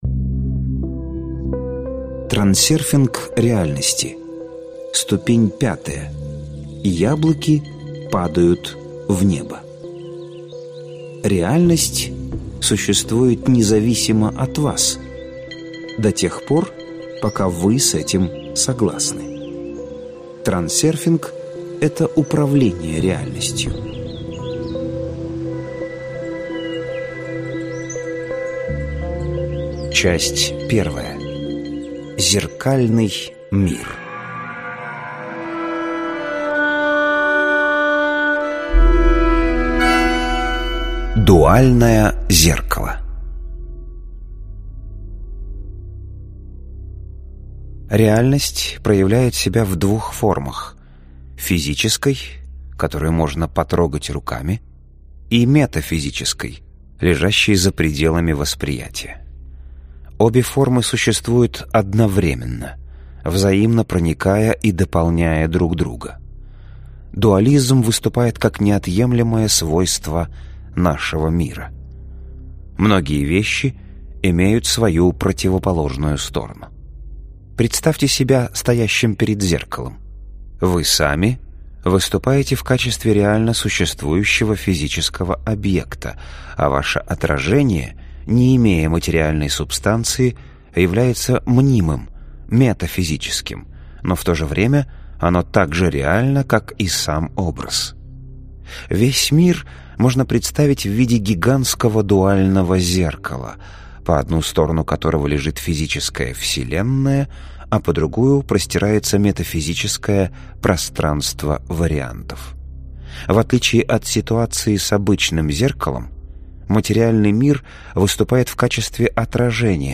Прослушать фрагмент аудиокниги Трансерфинг реальности.